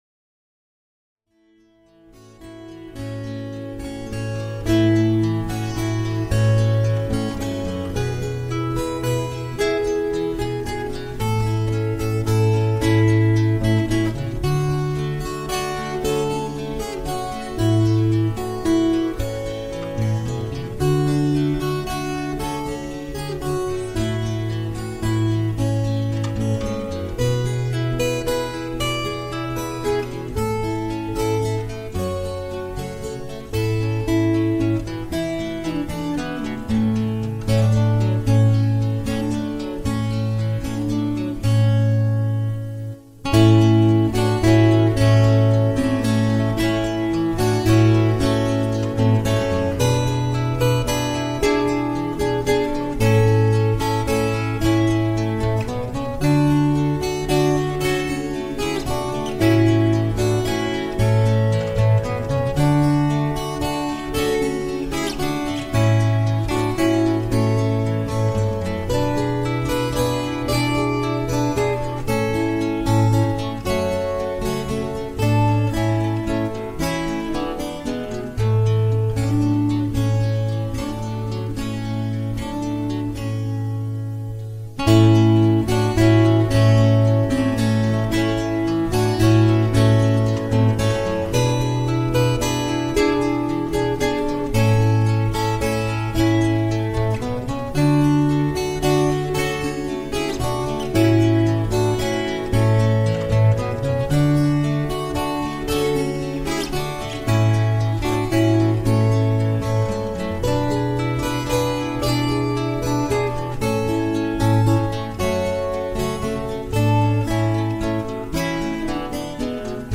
Das "Ewige Lied" - Silent Night - Instrumental auf der Gitarre ...